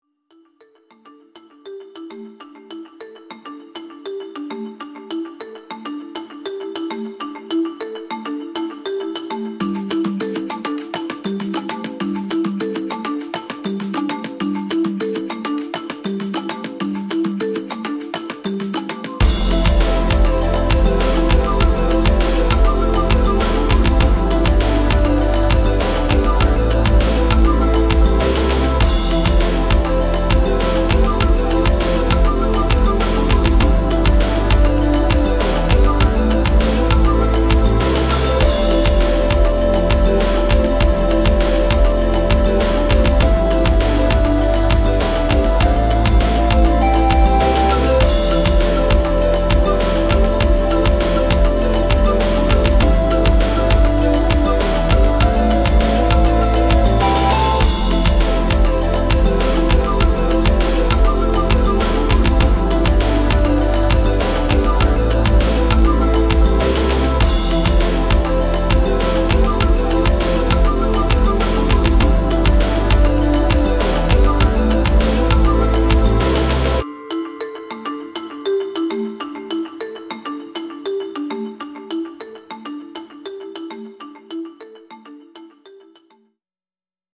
BGM
スローテンポ穏やか